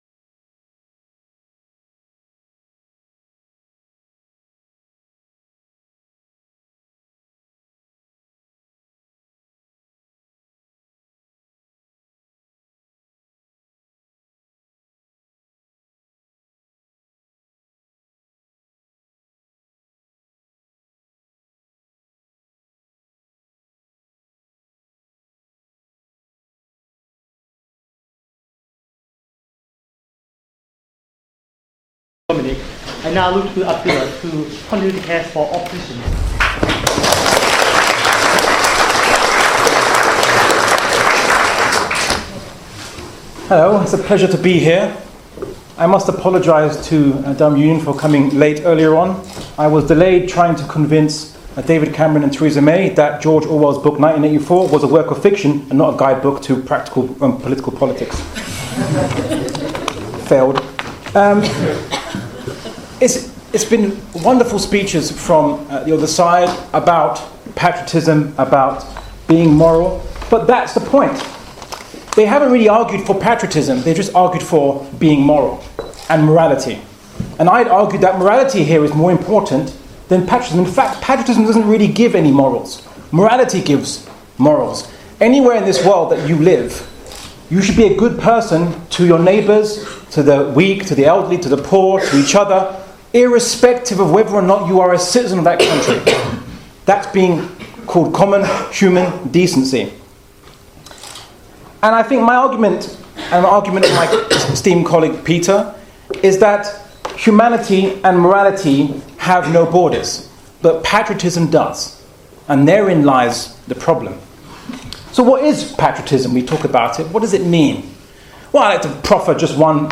speech against Pride in Patriotism at UK Debate